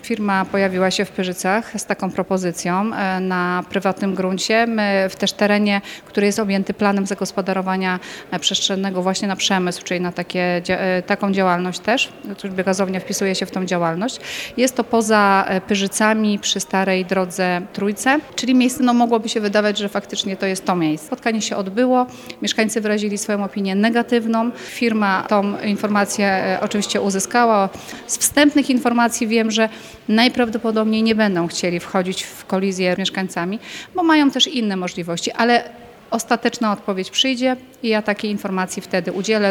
Kilka tygodni temu informowaliśmy o planach budowy biogazowni w okolicach Pyrzyc przez norweskiego inwestora. Jak zakończyło się spotkanie inwestora z mieszkańcami, o tym mówi Marzena Podzińska – Burmistrz Pyrzyc.